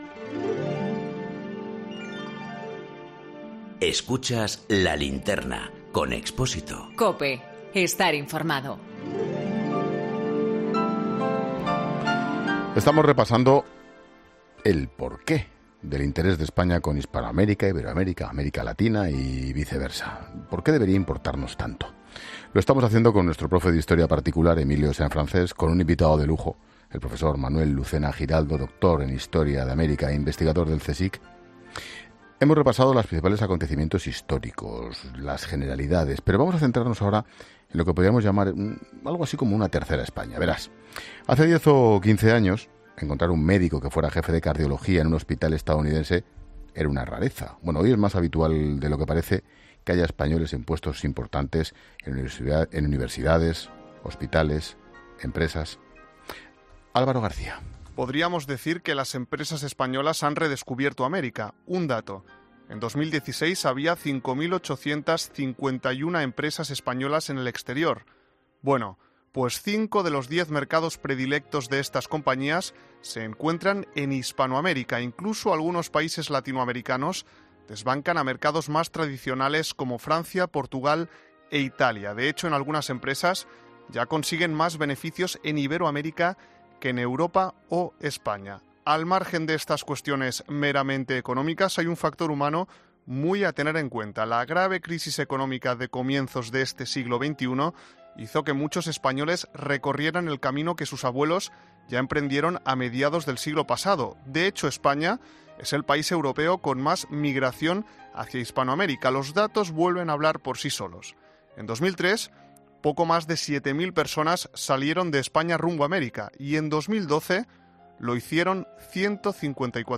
con dos invitados de lujo